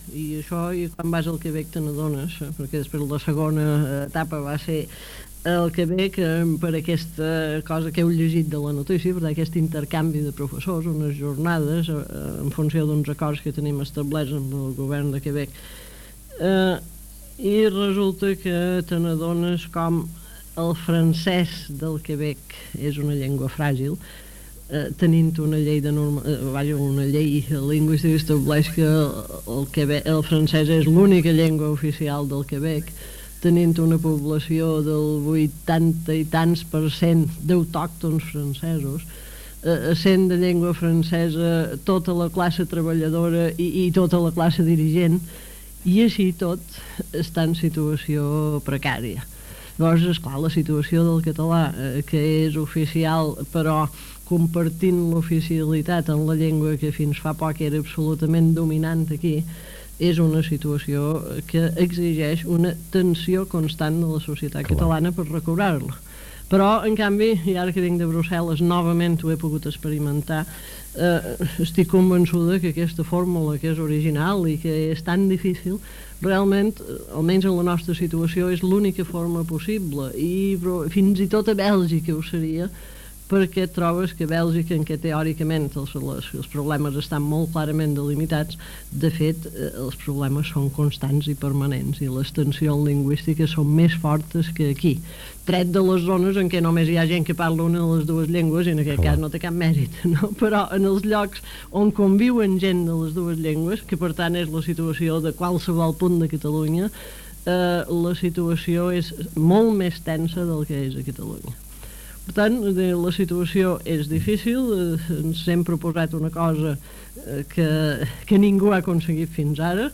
Entrevista a la filòloga Aina Moll, Directora general de Política Lingüística de la Generalitat de Catalunya, sobre la situació del català i els Països Catalans